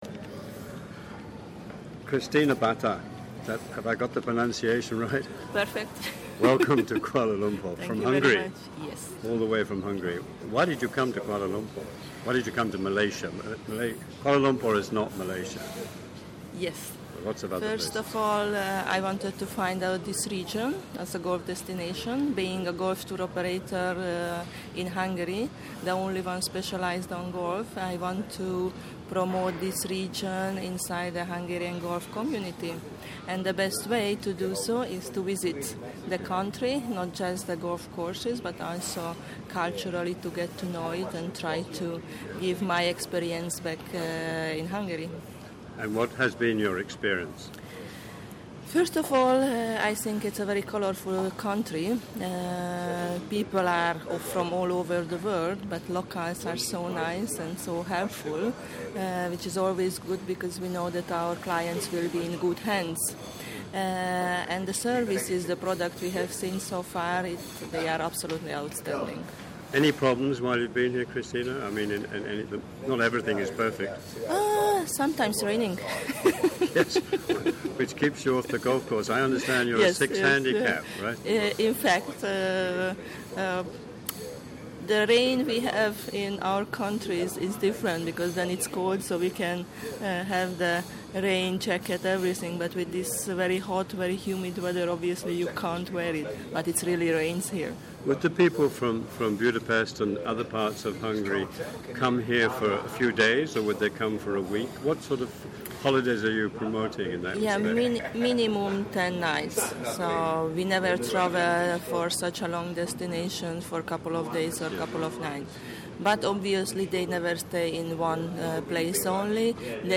MGTA interviews